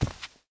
cloth4.ogg